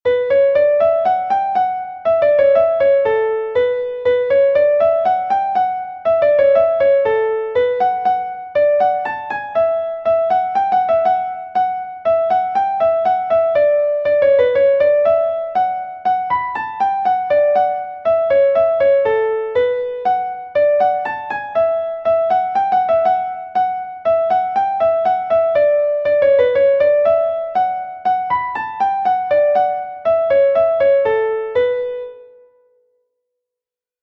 Gavotte de Bretagne